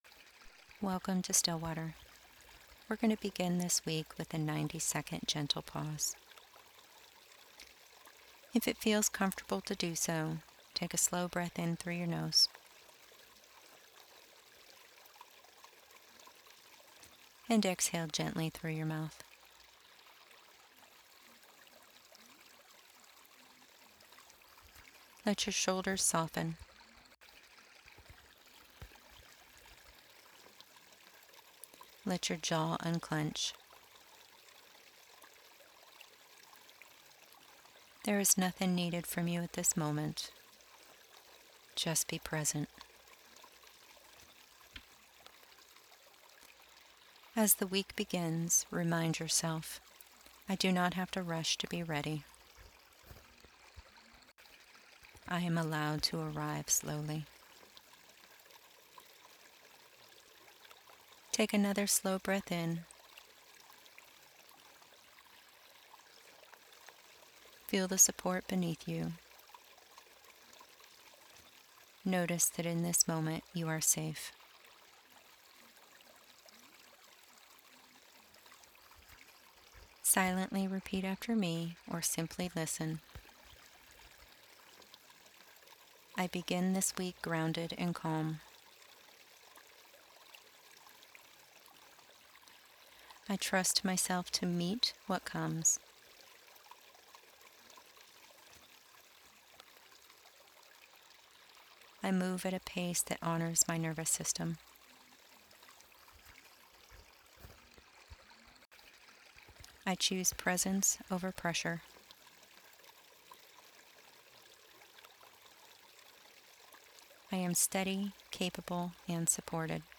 Here, you’ll find free affirmations and short guided meditations designed to help you pause, regulate, and return to calm in ways that feel realistic and accessible.